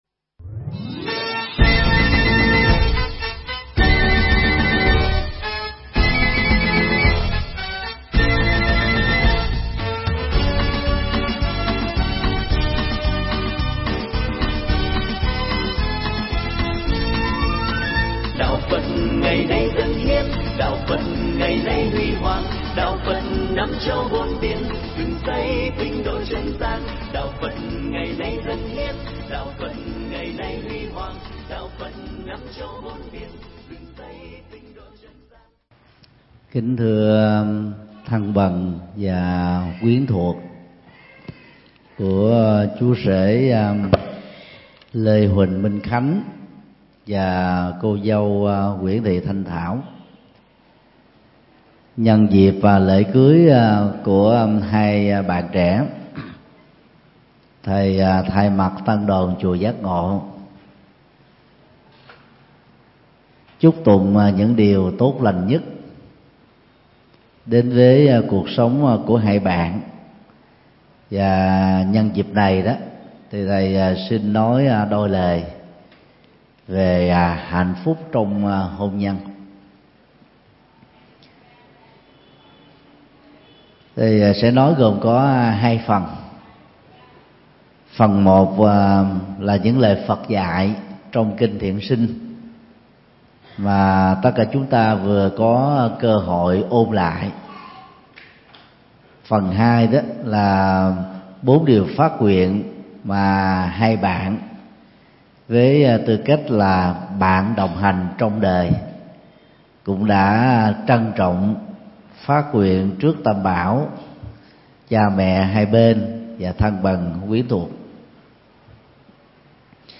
Mp3 Pháp Thoại Hạnh Phúc Trong Hôn Nhân – Thượng Tọa Thích Nhật Từ giảng trong lễ Hằng Thuận tại chùa Giác Ngộ, ngày 8 tháng 4 năm 2017